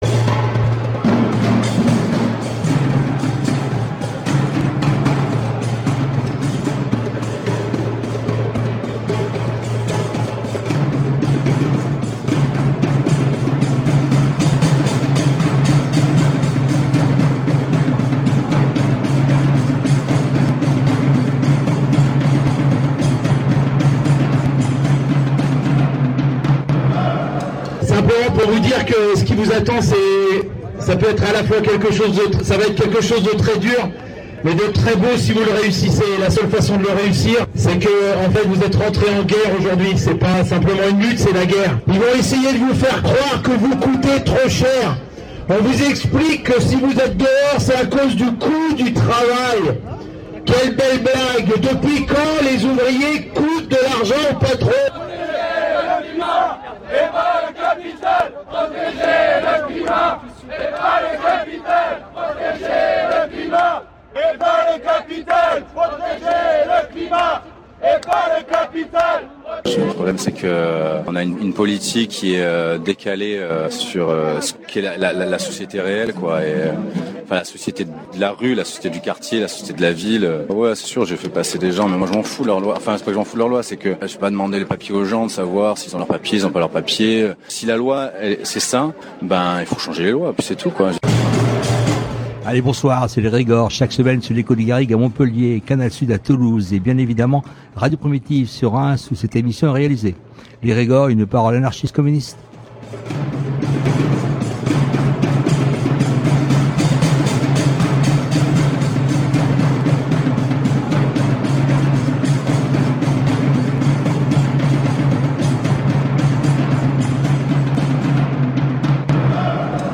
Le géographe suédois Andreas Malm, que nous allons entendre, tente d’apporter des réponses à ces questionnements, en expliquant en quoi la crise sanitaire est aussi une crise politique et une crise du capitalisme. Il était alors l’invité en août 2021 du site d’information Le média. Entretien que nous allons entendre. classé dans : actu société Qui sommes nous ?